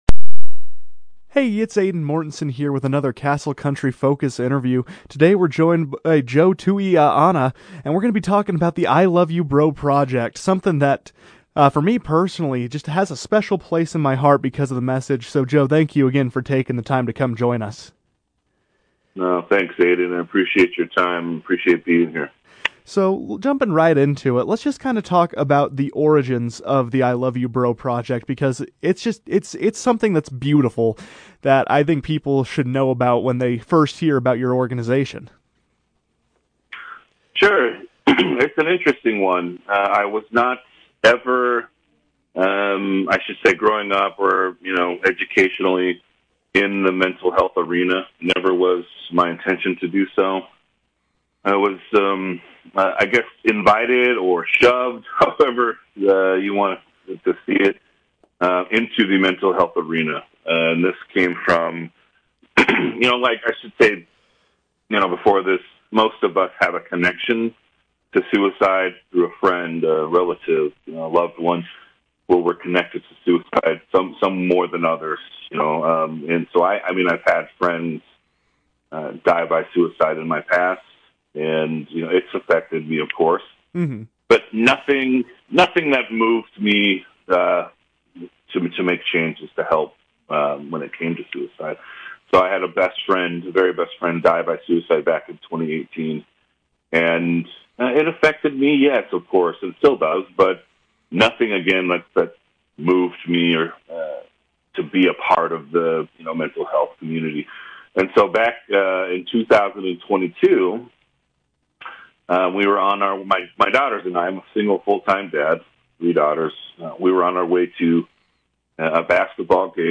“Together Through Fire” A Conversation with ‘I Love You